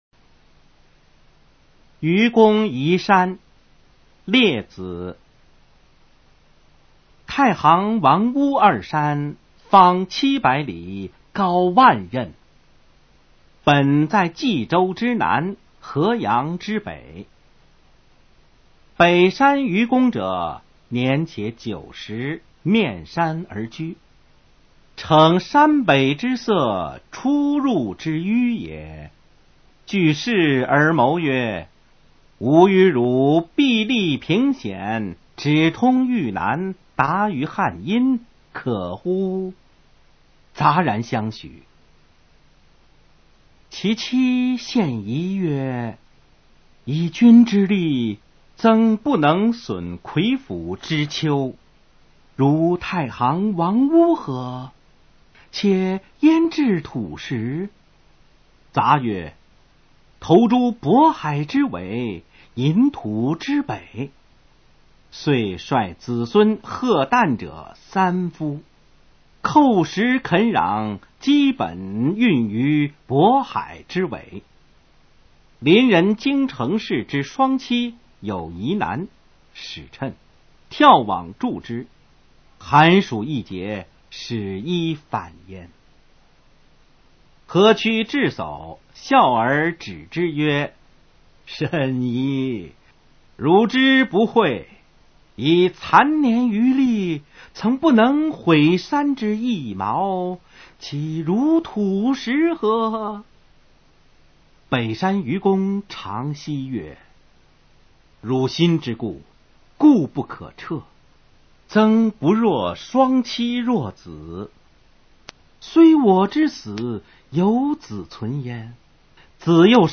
《愚公移山》原文与译文（含mp3朗读）　/ 佚名